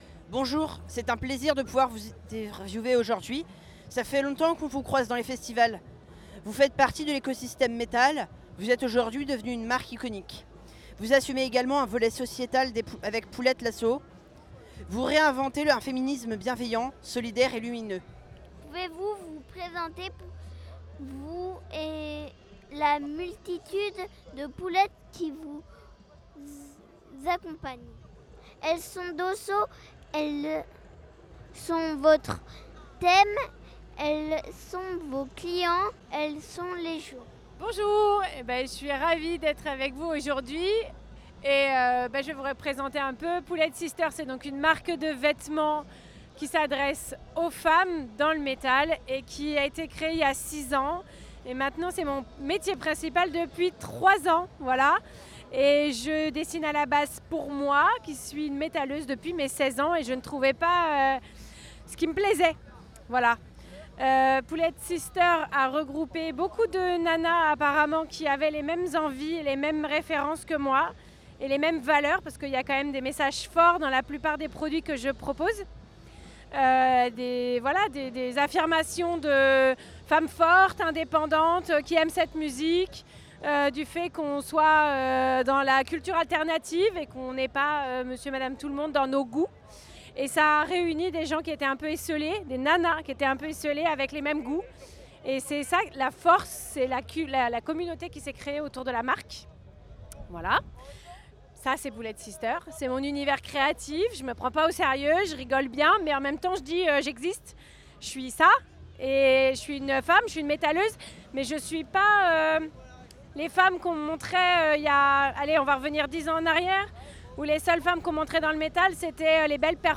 HELLFEST 2025 - rencontres autour de l'écosystème du Metal – Radio U